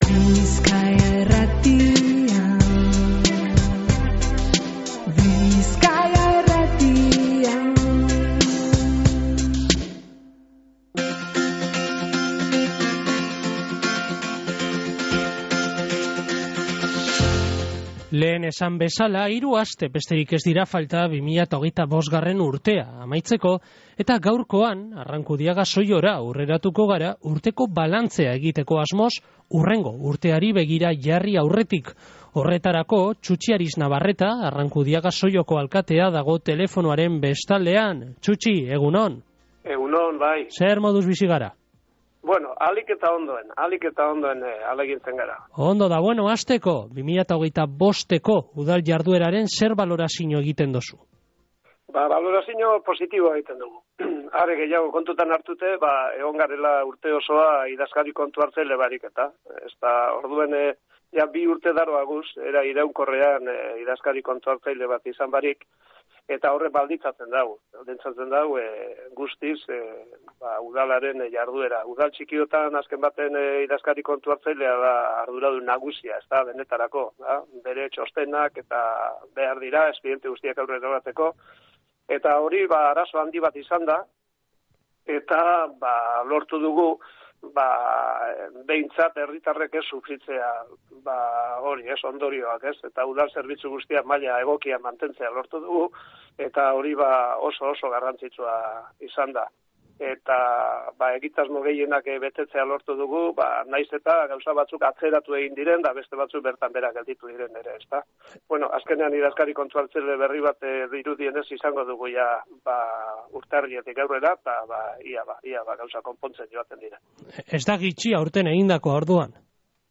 Txutxi Ariznabarreta, Arrankudiaga-Zolloko alkatea / Bizkaia Irratia
Horretarako, Txutxi Ariznabarreta Arrankudiaga-Zolloko alkateagaz egin dogu berba Goizeko Izarretan irratsaioan. Ariznabarretak urteko balantze positiboa egin dau eta esan deusku 2026ko aurrekontuak prestatzen dabilzala.